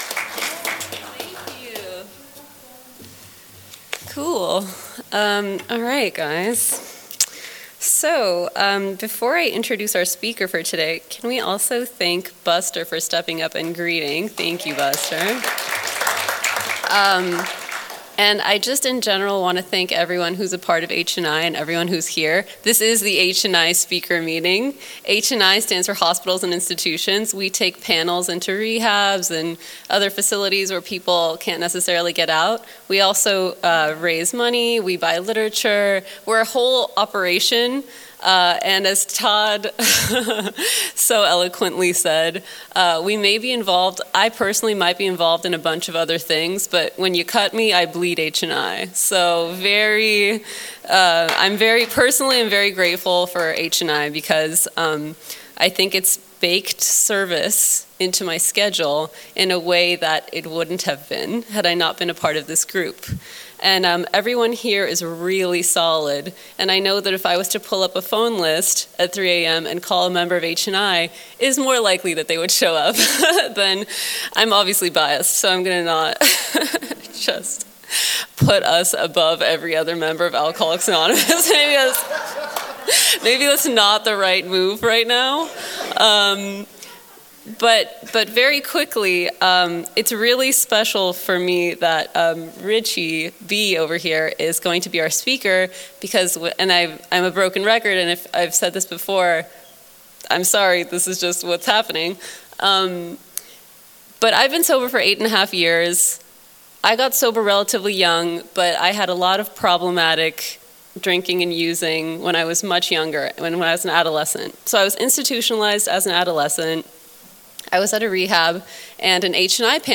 47th Annual San Fernando Valley AA Convention